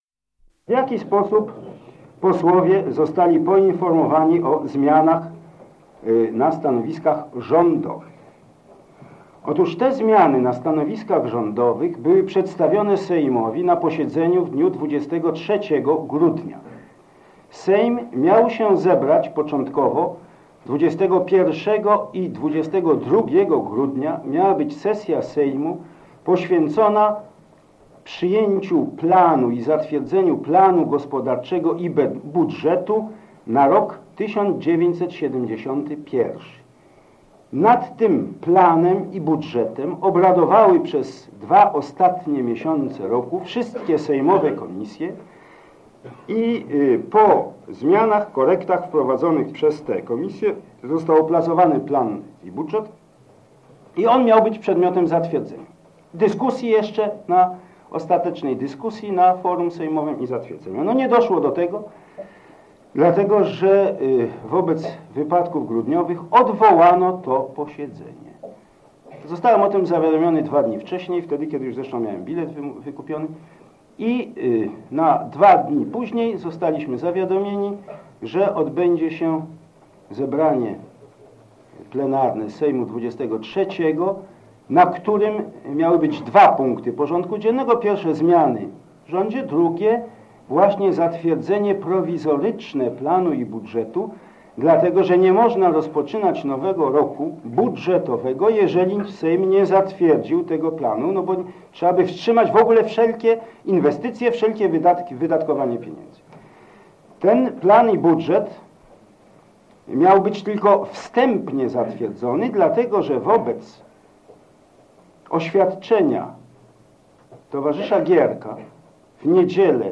Wystąpienie
na spotkaniu w DS 9 objaśniające ostatnie decyzje Sejmu PRL